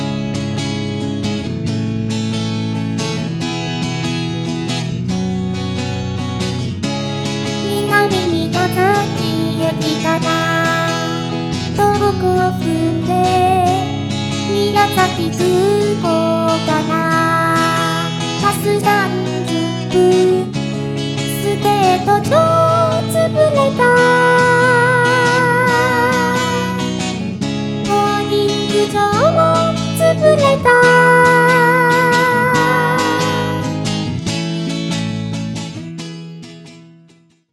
せっかく体験版を入手しましたなので調教してみたってことでした。曲は宮交シティのうた。